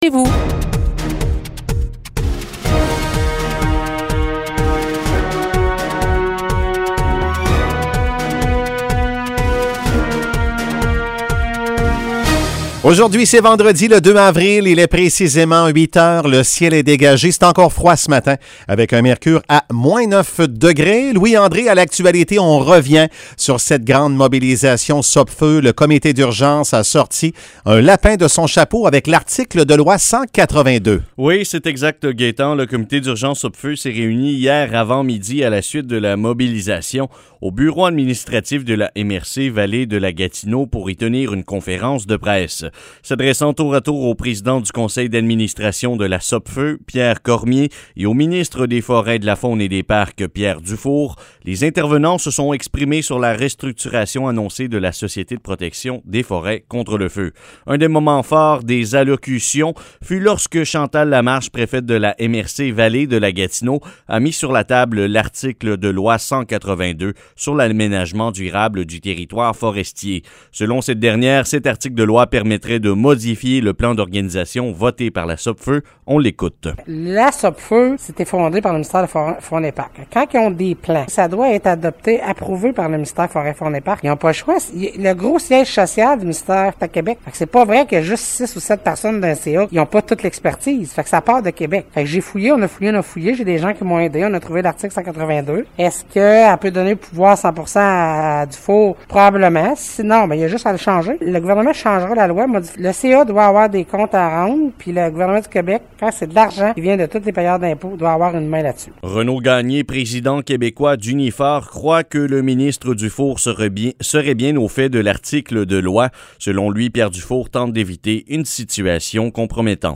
Nouvelles locales - 2 Avril 2021 - 8 h